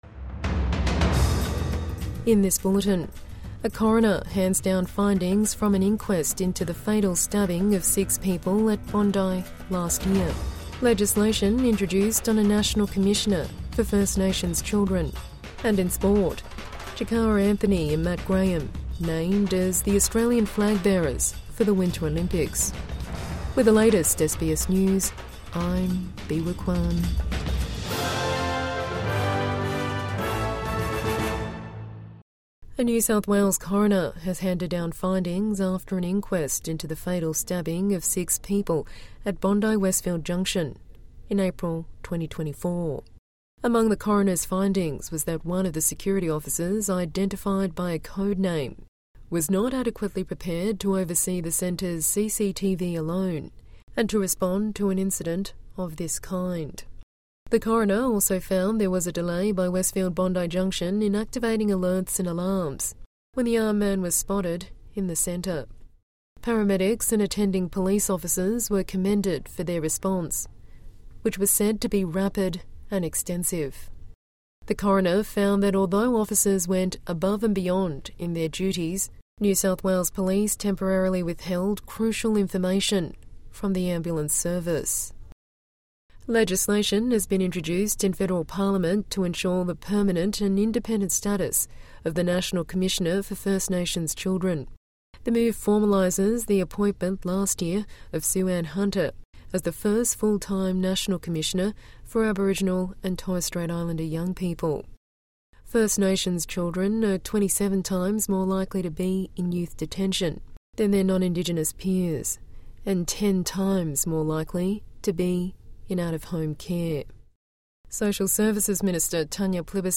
Bondi stabbing inquest findings handed down | Midday News Bulletin 5 February 2026